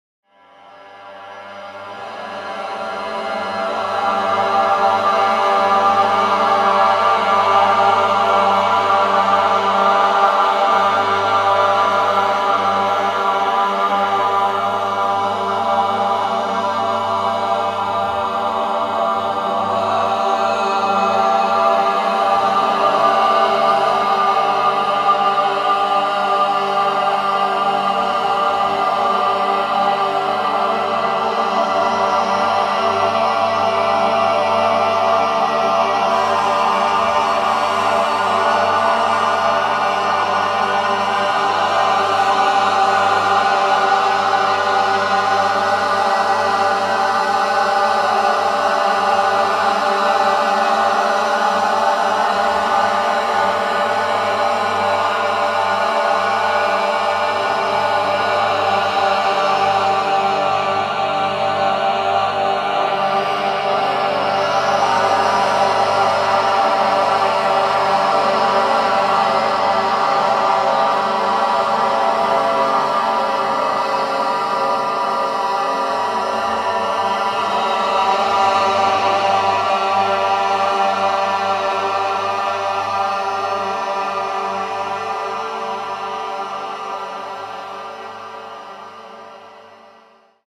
Qui potrai ascoltare alcune parti dei lavori che faremo all'interno del seminario, la durata dei brani è di circa due minuti cadauna
VOCALIZZAZIONI MATTINA-PARTE DI A.mp3